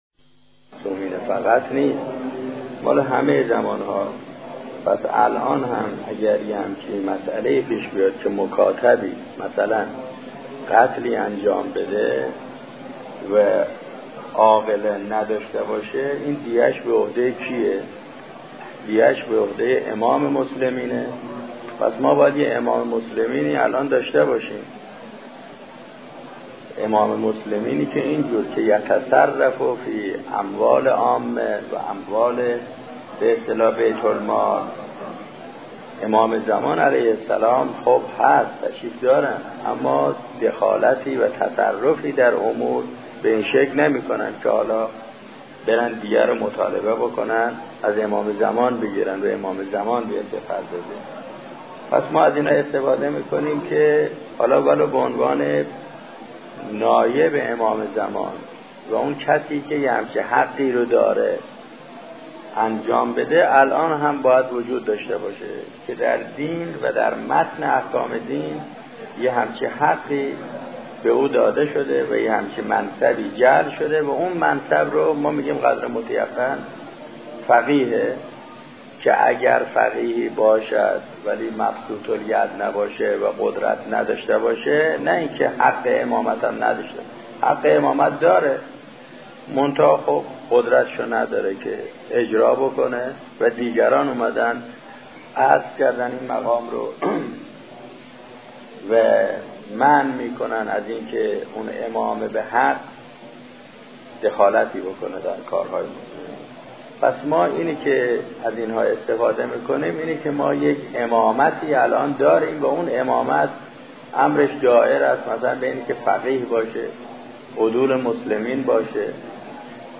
استاد آذري قمي - ولايت فقيه | مرجع دانلود دروس صوتی حوزه علمیه دفتر تبلیغات اسلامی قم- بیان